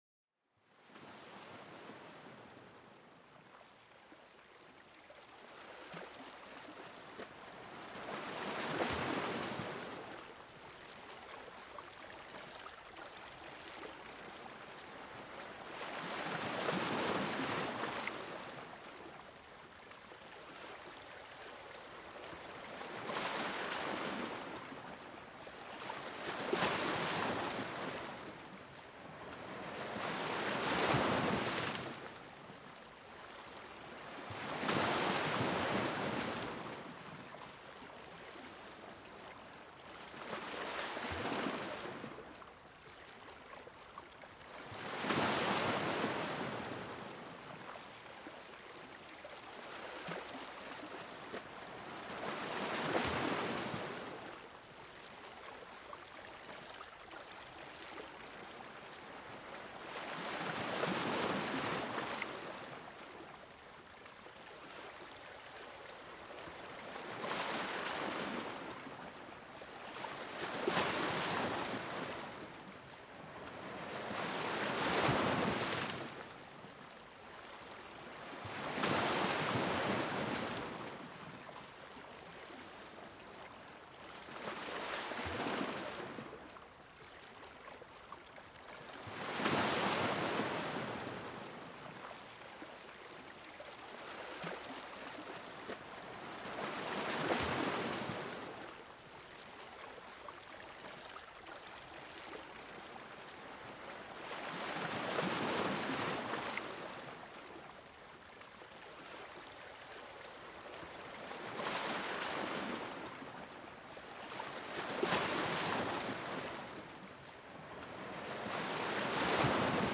Escucha el suave susurro de las olas acariciando la orilla, un sonido natural perfecto para escapar del estrés diario. Este podcast te lleva al corazón de una playa aislada donde el océano canta una melodía relajante. Déjate arrullar por este sonido encantador y sumérgete en una profunda relajación.Este podcast está diseñado para ayudarte a relajarte, encontrar paz interior y promover un sueño reparador.